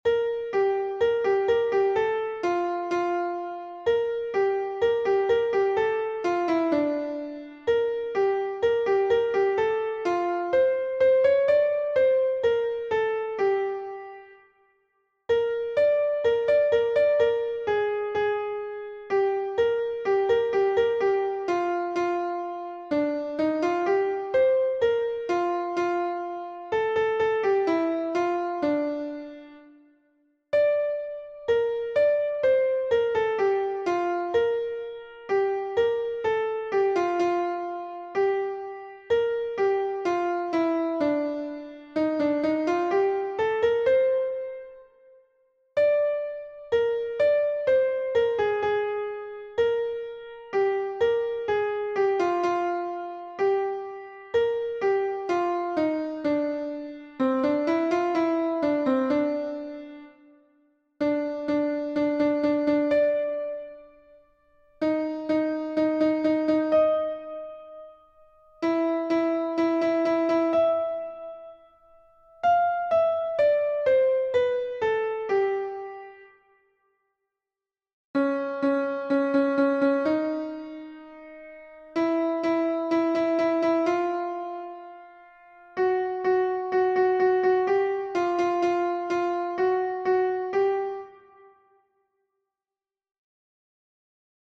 LA_SOUPE_A_LA_SORCIERE_voix1